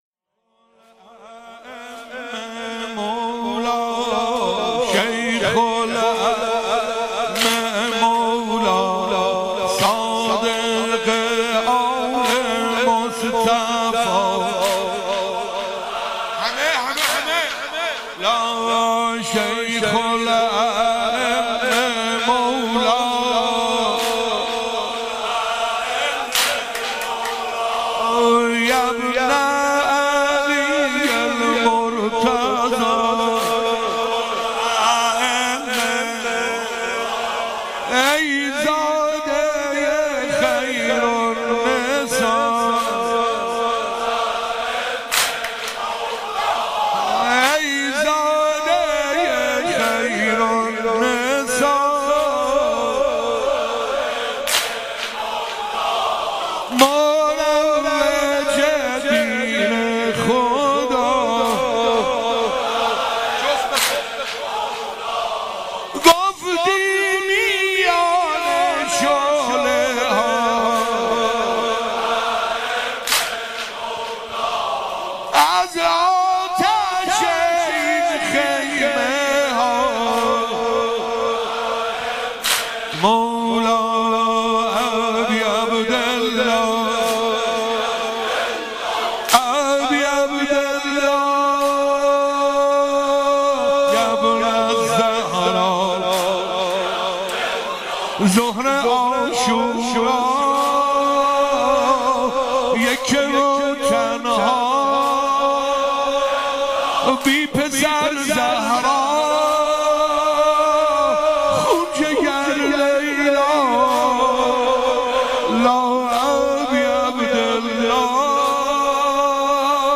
شهادت امام صادق (ع)